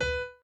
b_pianochord_v100l16-3o5b.ogg